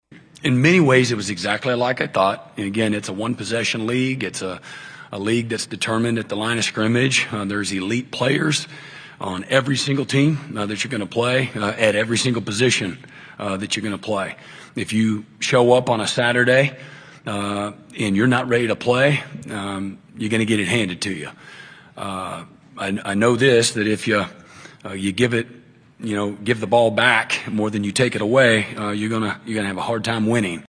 Oklahoma head football coach Brent Venables and the Sooner contingent talked on Wednesday at SEC Media Days in Atlanta, as OU tries to bounce back under Venables in his fourth season as head man.